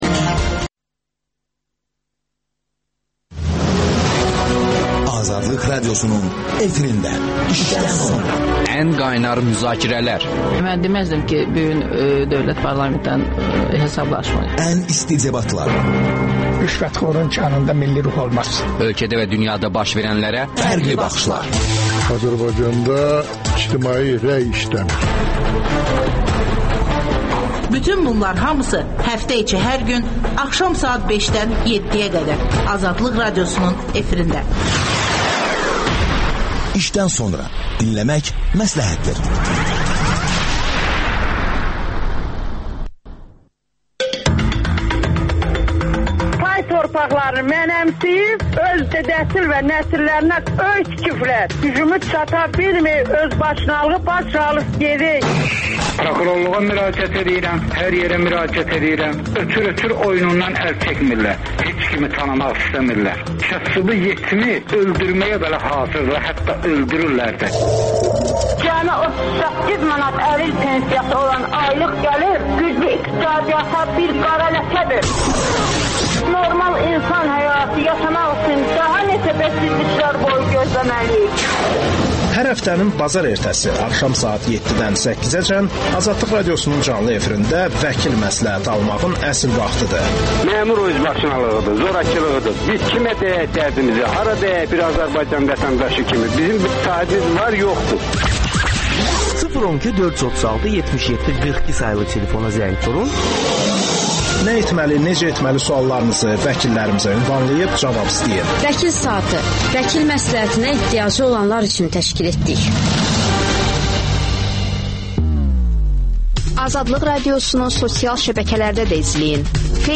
Əkrəm Əylisli canlı efirdə sualları cavablandırır.